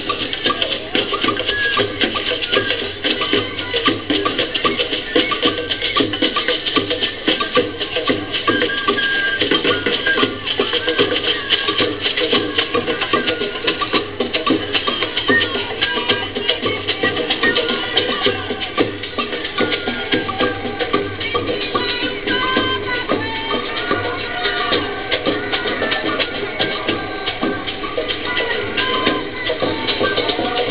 Horns, cheers, and excitement.
That's what you would have seen and heard at the annual African Day Parade in Harlem, September 15th.